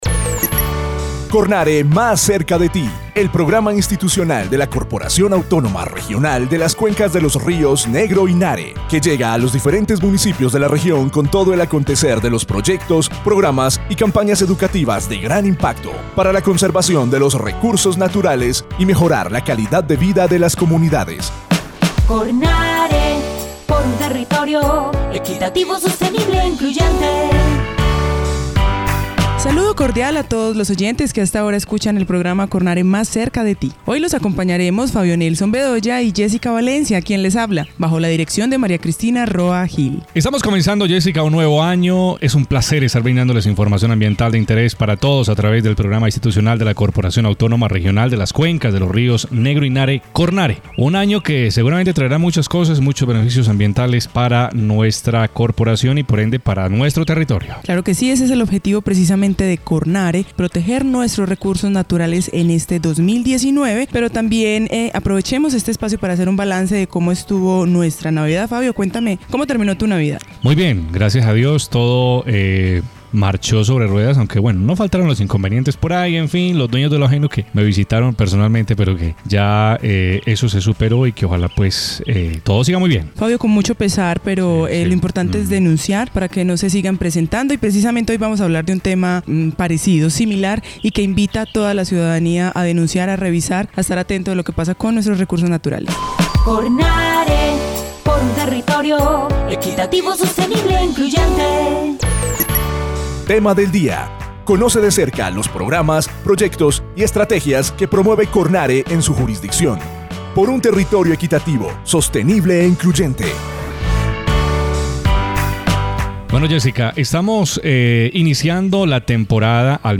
Programa de radio